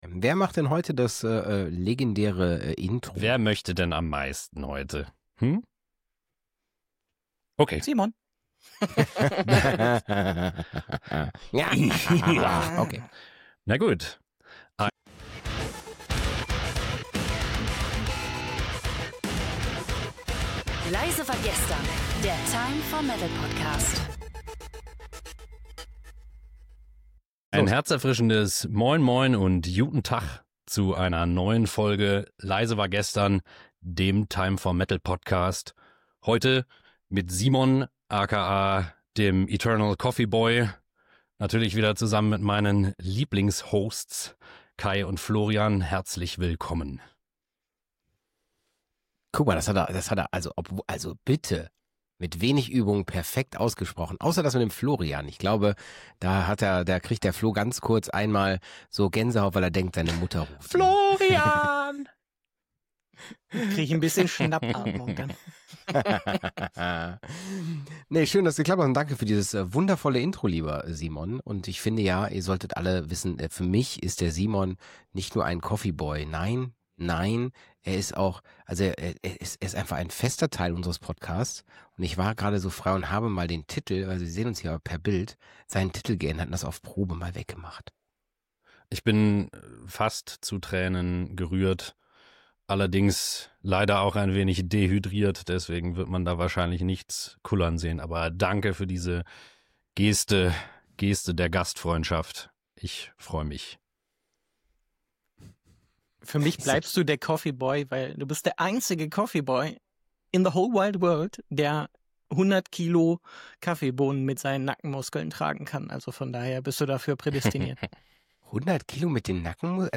In dieser Folge geht’s drunter und drüber: Die Hosts begrüßen euch wie immer herzlich – und steigen dann direkt in ein „Themen-Roulette“ der Extraklasse ein. Vom Erinnern an legendäre Interviews bis zu den härtesten Live-Auftritten von Cannibal Corpse : Es wird gelacht, philosophiert und natürlich ordentlich gebangt! Zensur in der Musik steht ebenso auf dem Programm wie die Frage: Was macht Headbanging eigentlich mit unserem Gehirn?